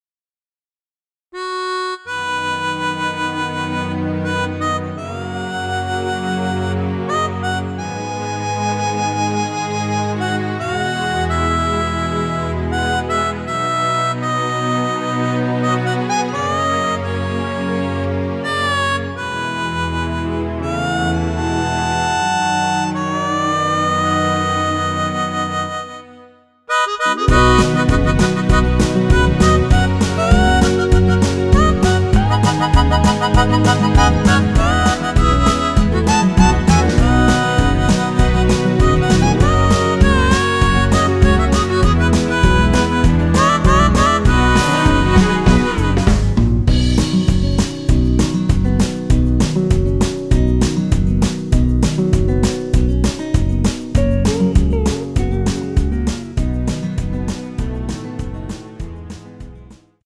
원키에서(-1)내린 MR입니다.
앞부분30초, 뒷부분30초씩 편집해서 올려 드리고 있습니다.
중간에 음이 끈어지고 다시 나오는 이유는